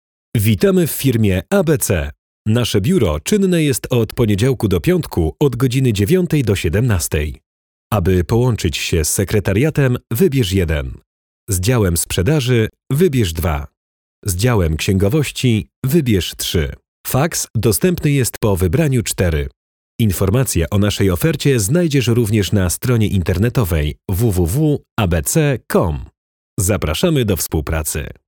Mężczyzna 30-50 lat
Przyjemny w odbiorze młody męski głos.
Nagranie lektorskie